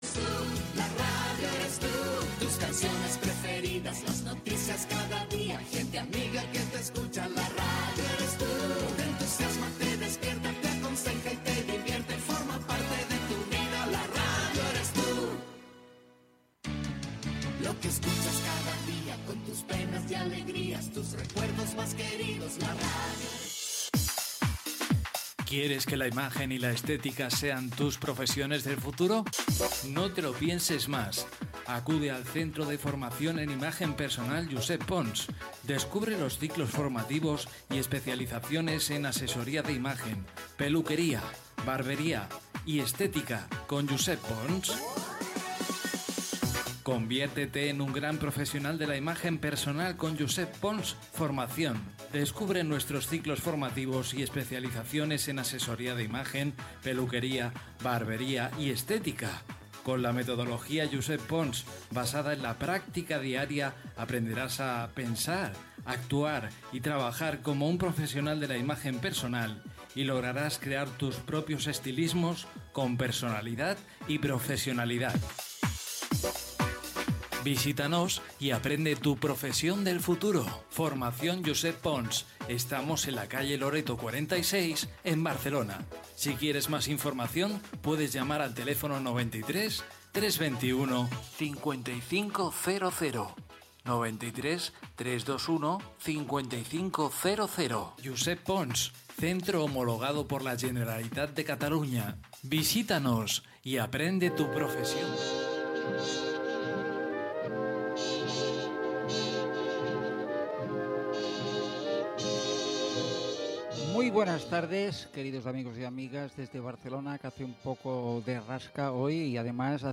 Cançó de la ràdio, publicitat, benvinguda amb un comentari sobre el temps
Gènere radiofònic Entreteniment